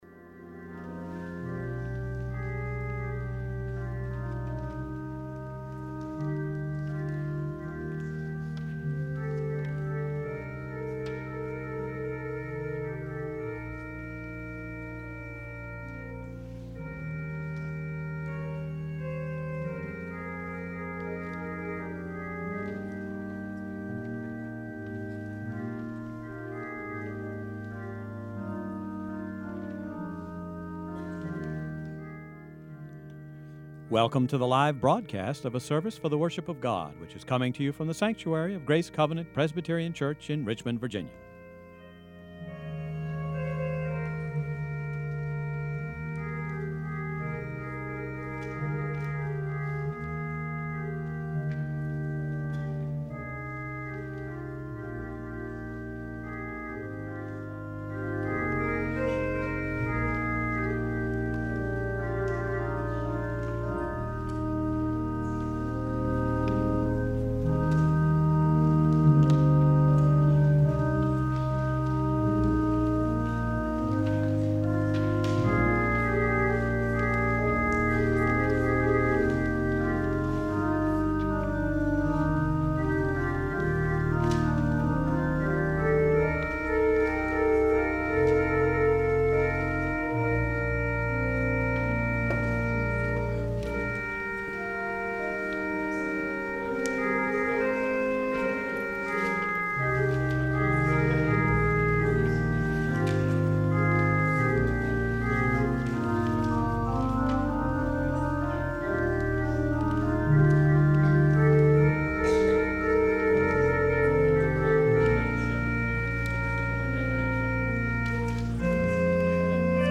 guest organist